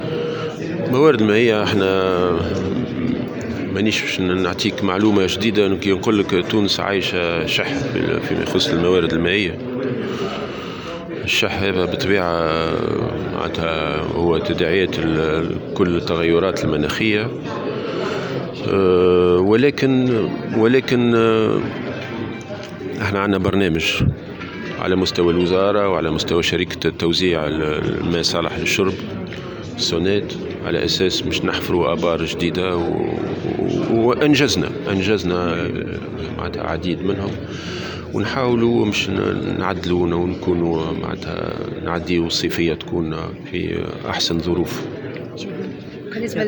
Il a ajouté dans une déclaration accordée à Tunisie Numérique que de nombreux puits ont été construites jusqu’à maintenant et ce, afin de gérer les pics de consommation pendant l’été.